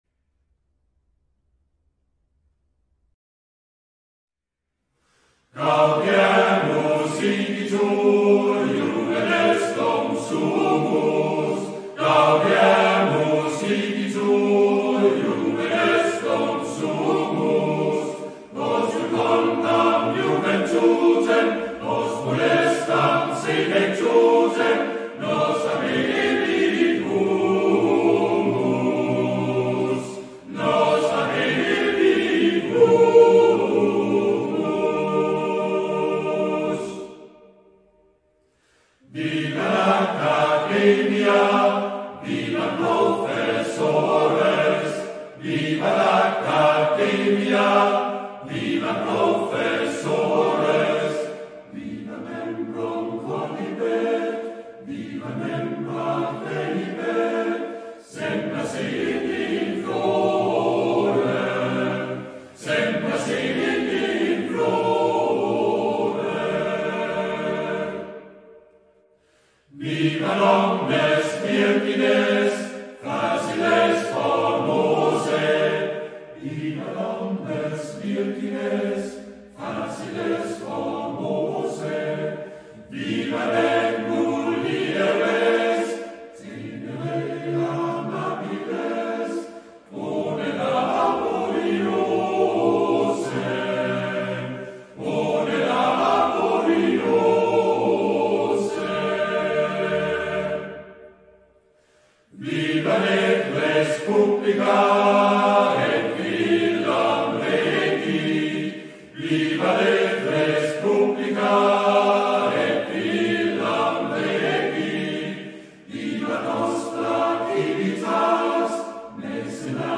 Мужской вокал